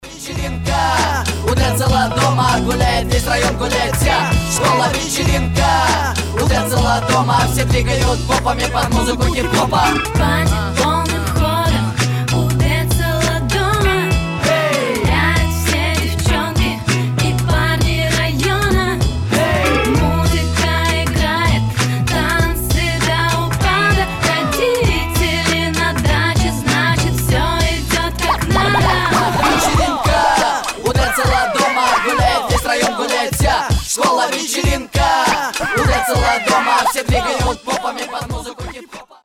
• Качество: 320, Stereo
Хип-хоп
веселые